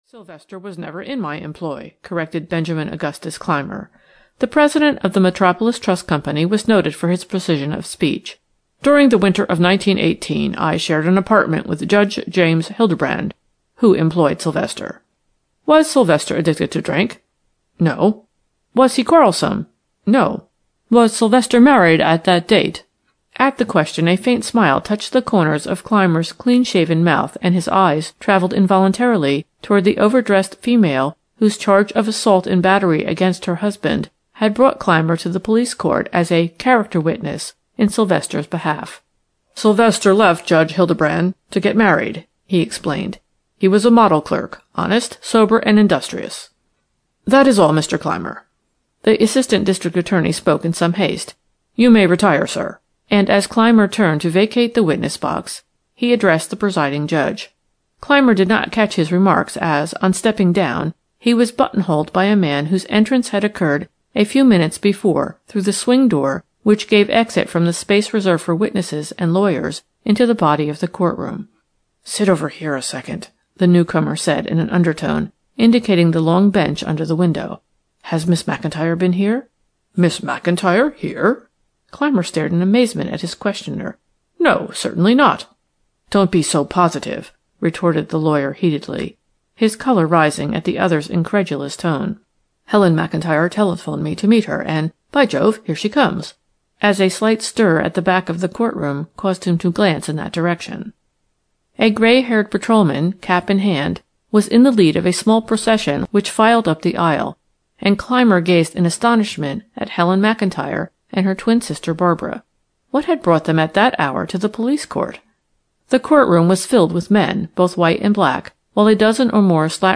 The Red Seal (EN) audiokniha
Ukázka z knihy